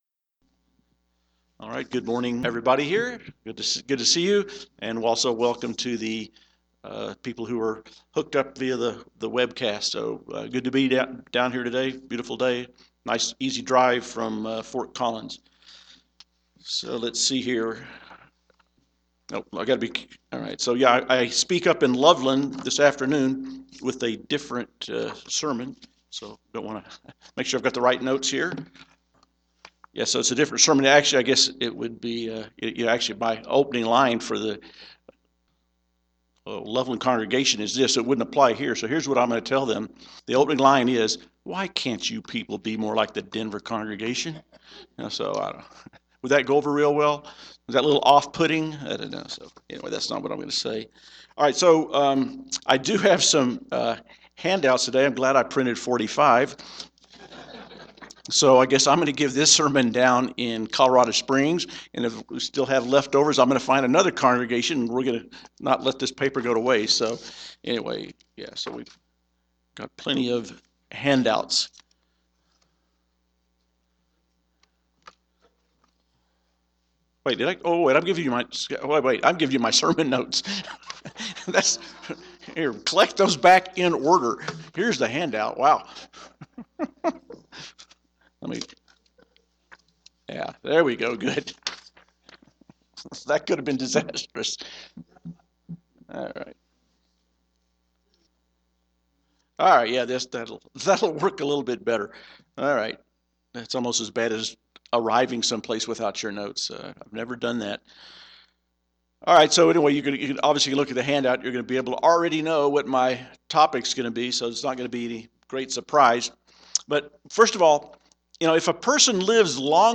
Sermons
Given in Denver, CO